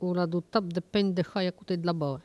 Patois - Archive
Catégorie Locution